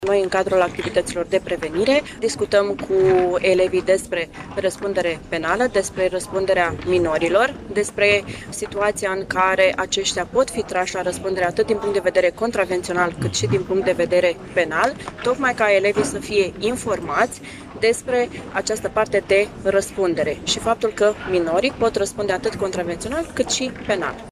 În Sala „Vasile Pogor” a Primăriei Iași, s-a desfășurat, astăzi, conferința cu tema ”Fenomenul bullying și Cyberbullying” în unitățile de învățământ din regiunea Nord-Est.